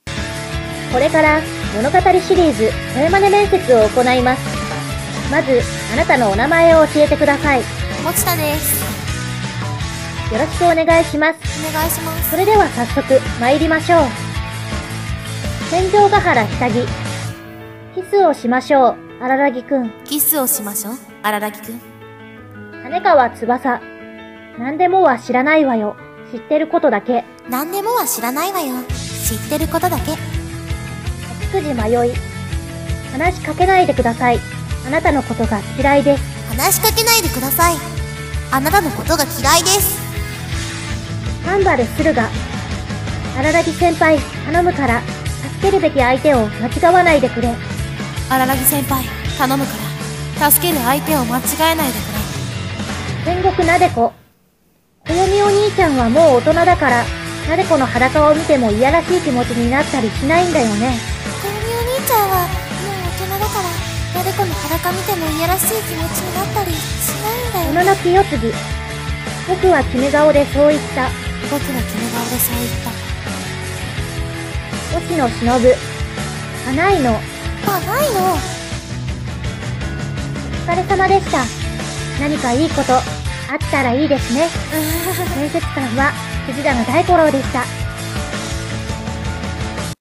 【化物語】物語シリーズ声真似面接【声真似】